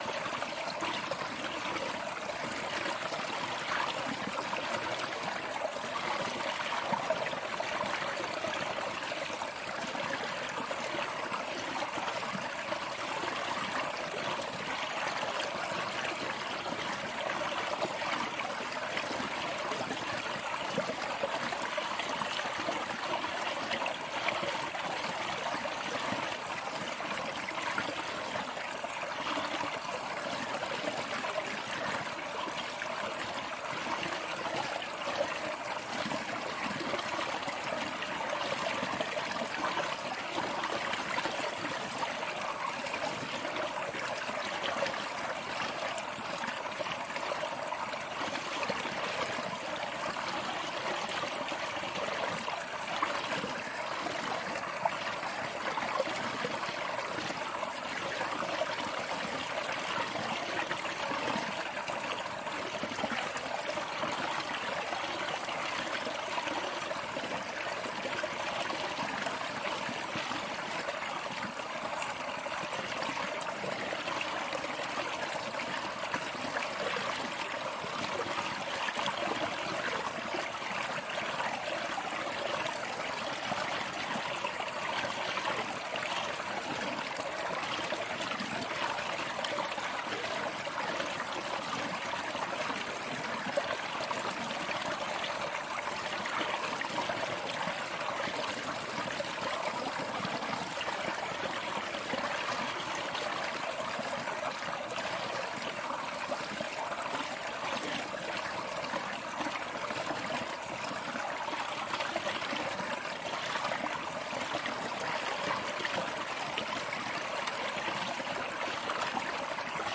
水 " 布鲁克冬季冰林2
描述：在安大略省农村的冬末记录水穿过冰冷的溪流。用H2N变焦记录仪记录。
Tag: 冬天 河流 森林 小溪 溪流 小溪